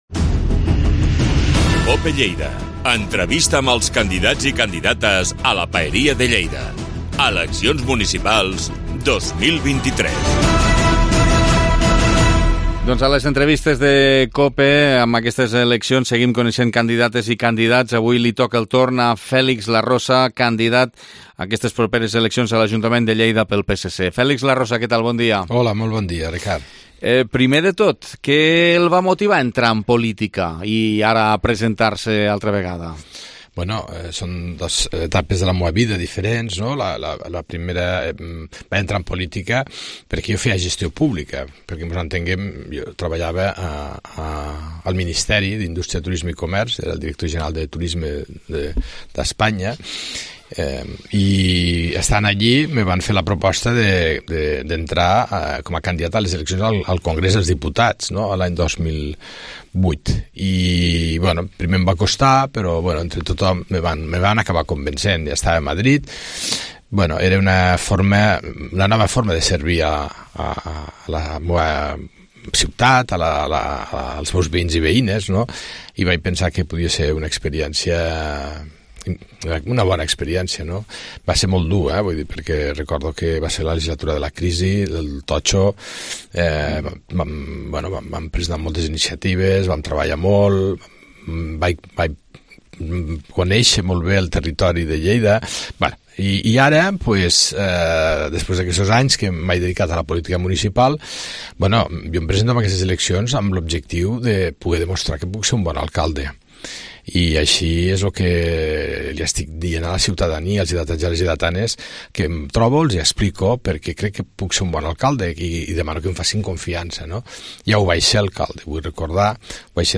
Entrevista Campanya Electoral 2023 - Fèlix Larrosa - PSC